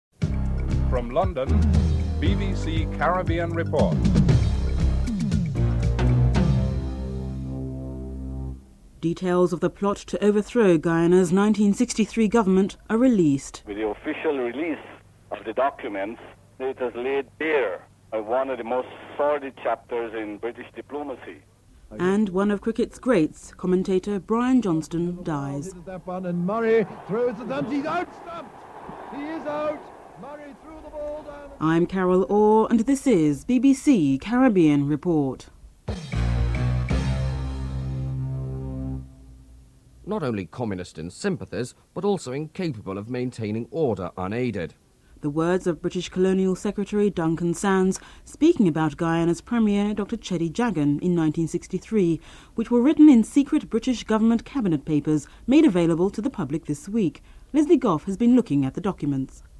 Headlines (00:00-00:38)
The words of British Colonial Secretary Duncan Sands can be heard reading excerpts from the secret papers.
Cheddi Jagan, who was in opposition at the time, can be heard saying the Guyana would not celebrate Independence because it was felt that imperialist, Britain, still had a stronghold on the Guyana.
4. Features an interview with Tony Cozier who had worked with Johnston for over two decades along with actual clips of radio programmes commentated by Johnston.